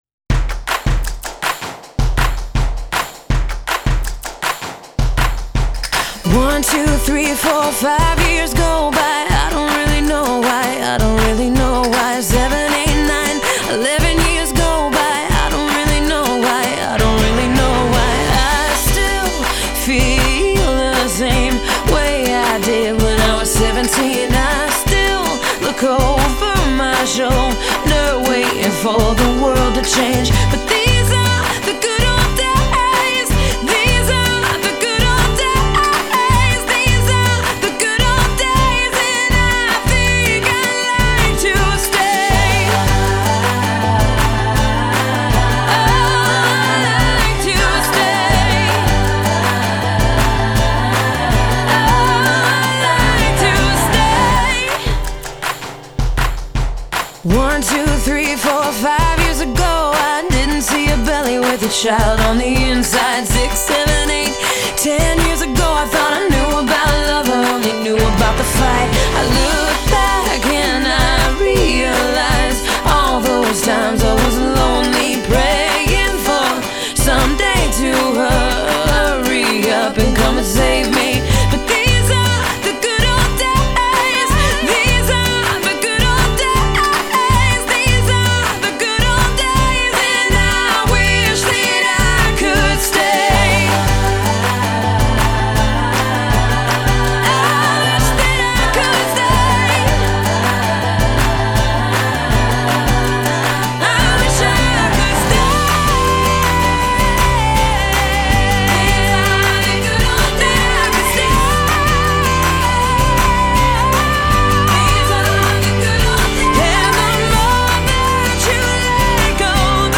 the song is a feel-good reminder to be where you are